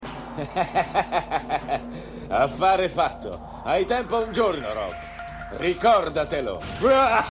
Nella seconda serie TV invece ha doppiato in quasi tutte le puntate ed ha prestato voce ai maggiori personaggi come Kaio e Hyo (prima voce) oppure ai più importanti shura come Kaiser, Gamon e Zebra.